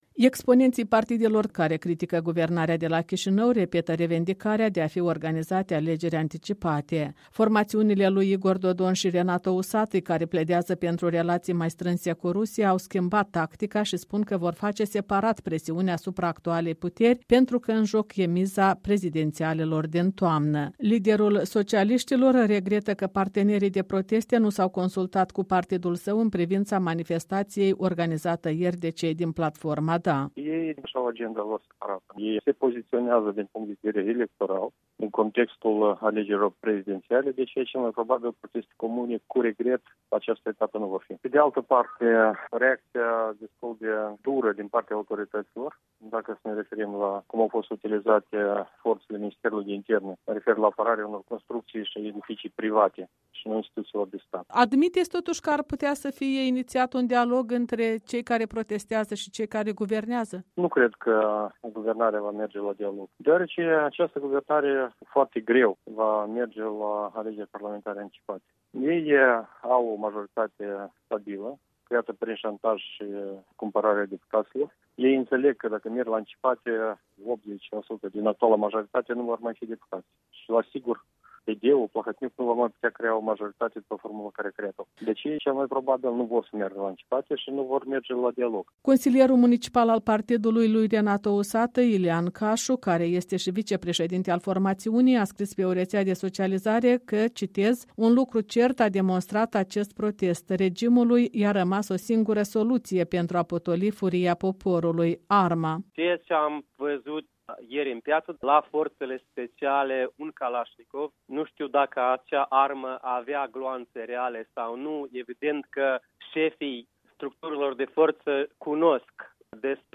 Deși declară că își doresc același lucru: eliminarea regimului pe care îl numesc „oligarhic”, acestea nu mai par a fi atât de unite, cum veți auzi în relatarea care a adunat câteva reacții la protestele de duminică din centrul Chișinăului